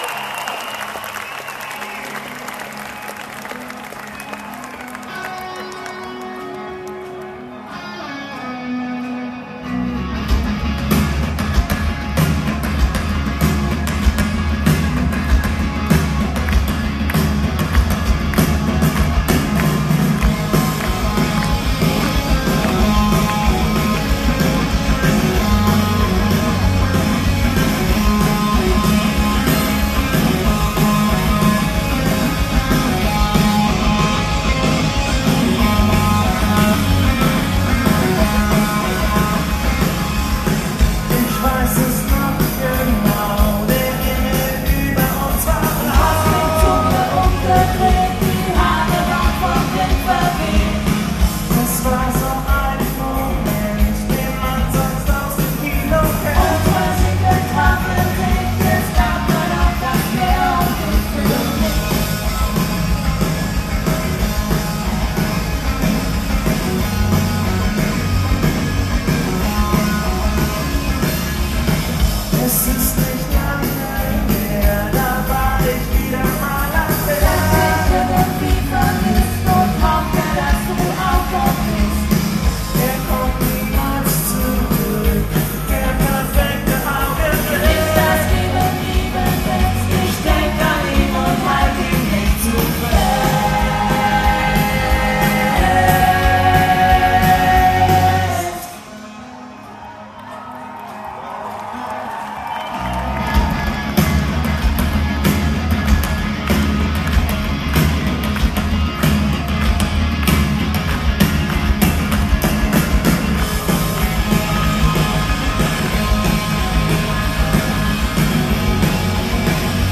Aufnahmegerät: Sharp IM-DR420H (Mono-Modus)
Mikrofon: Sony ECM-T6 (Mono)